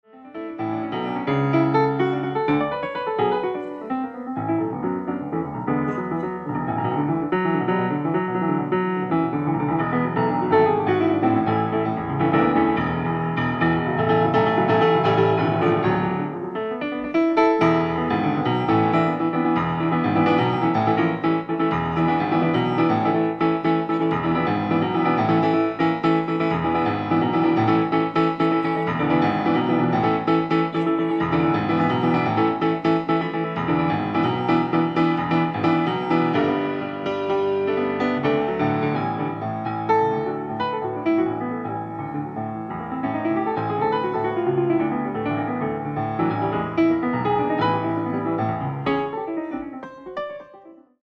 PIANO SOLO